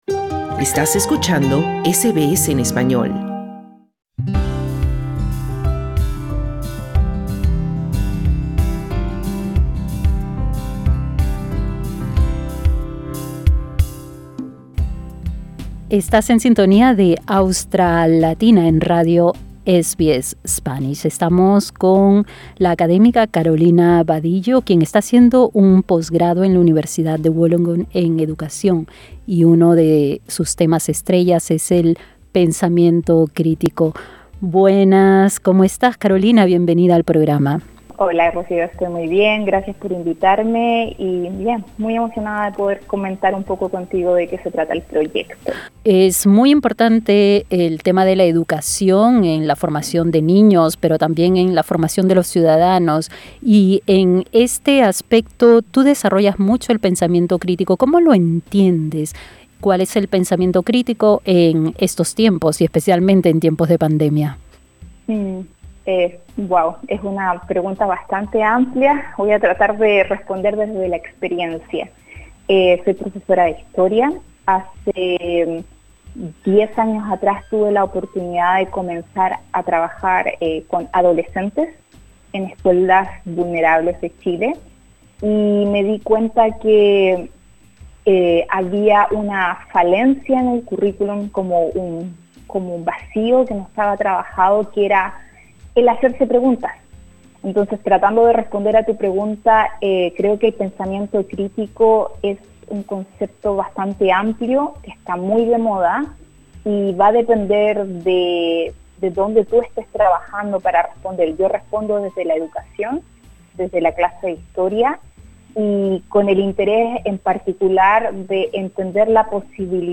Escucha su entrevista con SBS Radio.